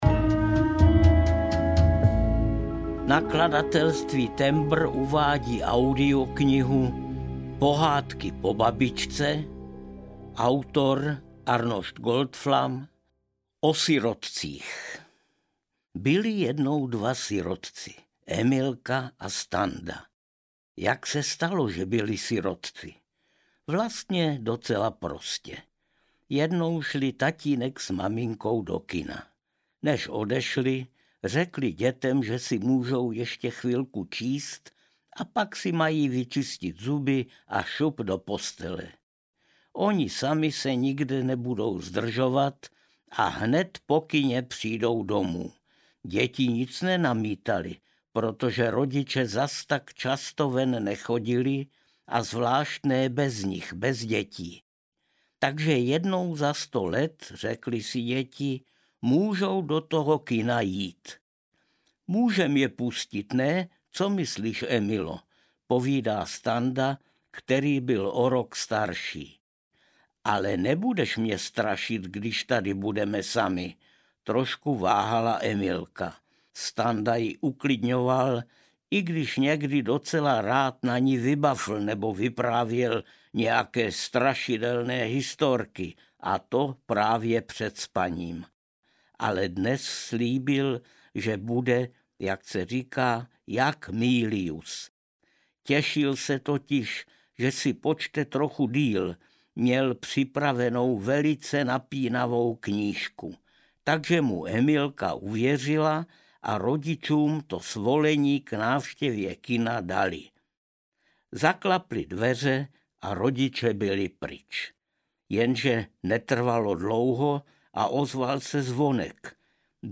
Pohádky po babičce audiokniha
Ukázka z knihy
• InterpretArnošt Goldflam